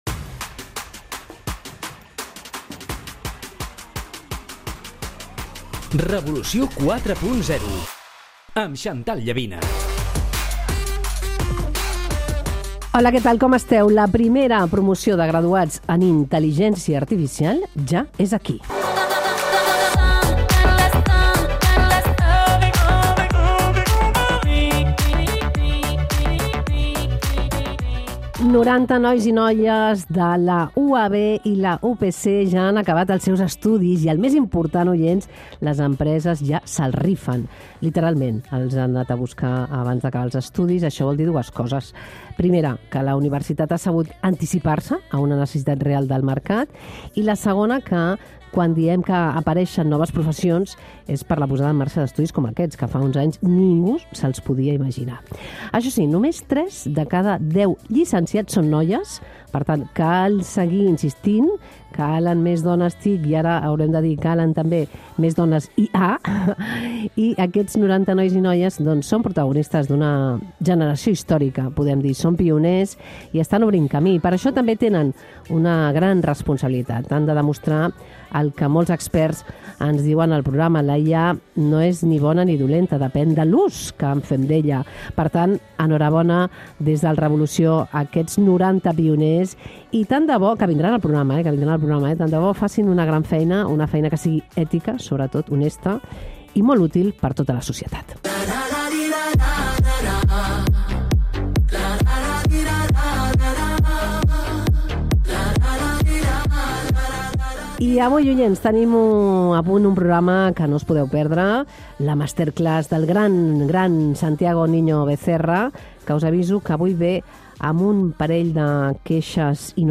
masterclass